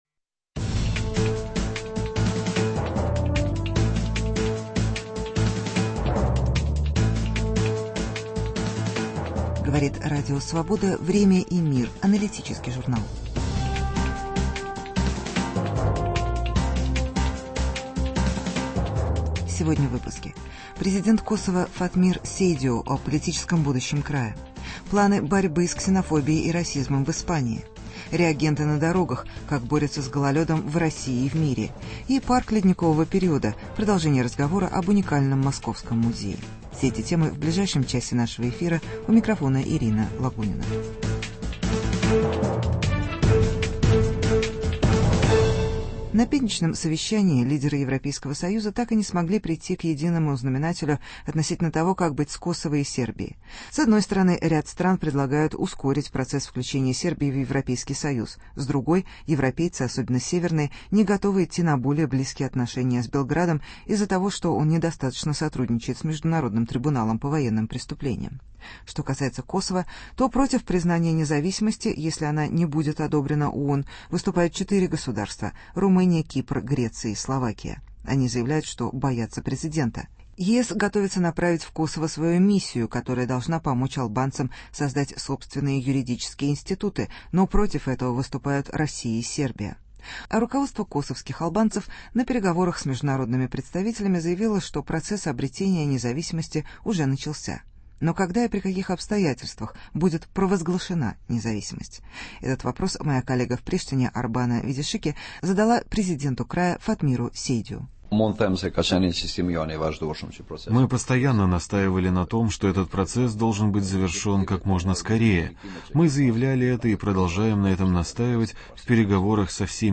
Интервью с президентом Косова. План борьбы с ксенофобией и расизмом в Испании.